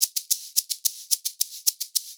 110 SHAKERS1.wav